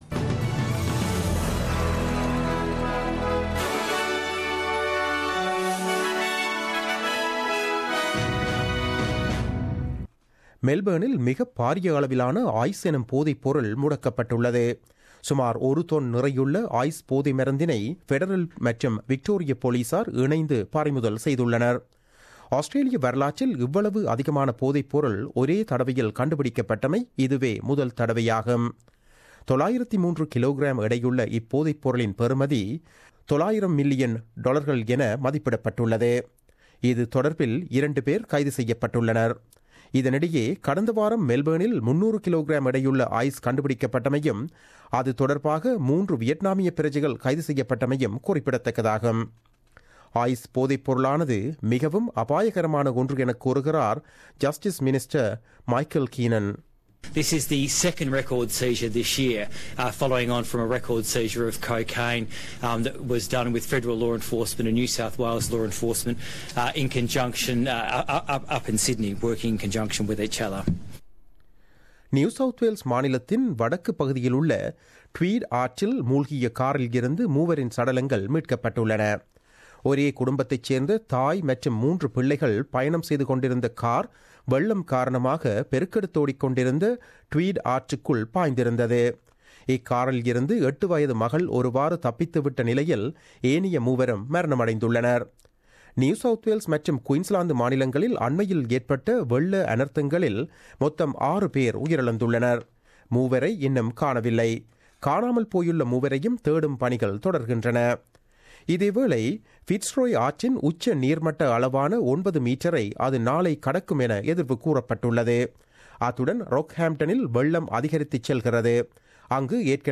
The news bulletin aired on Wednesday 05 April 2017 at 8pm.